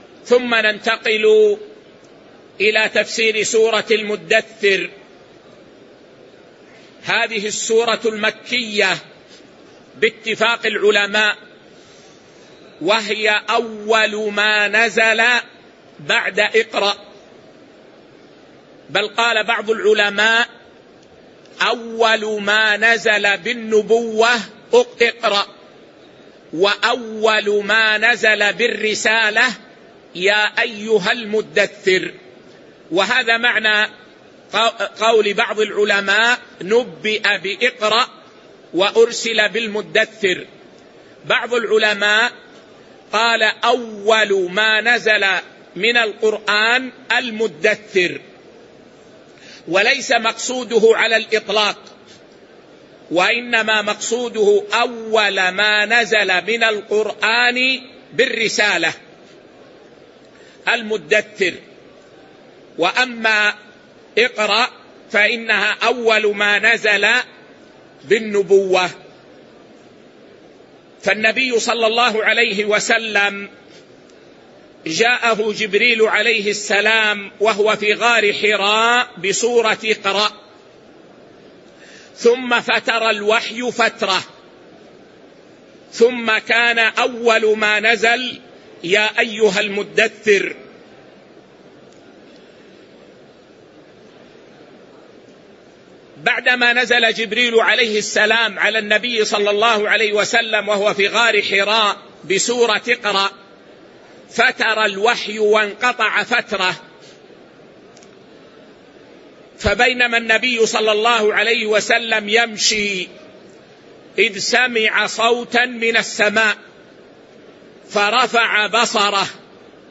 الدرس 22 تفسير سورة المدثر 1 من آية 01-29